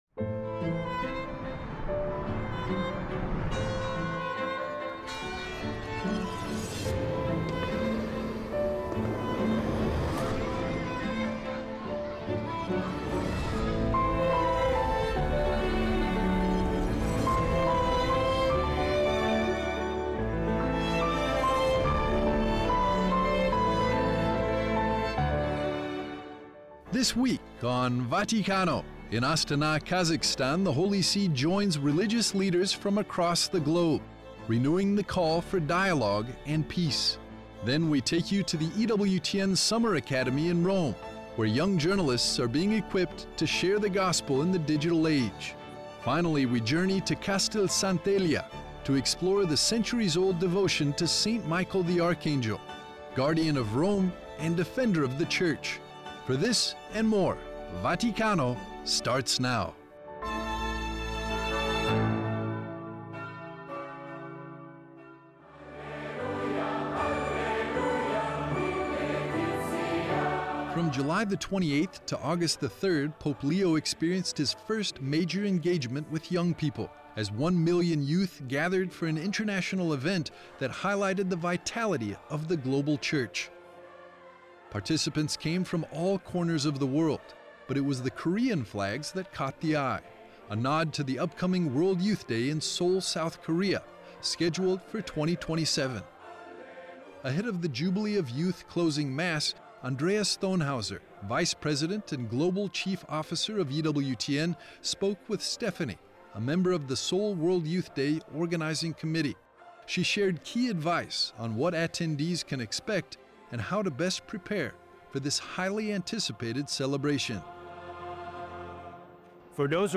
Presenting the latest news from the Vatican with excerpts and analysis of the Holy Father’s recent audiences and writings, newsmaker interviews, highlights of recent events, and feature segments- all from the heart of the Universal Church.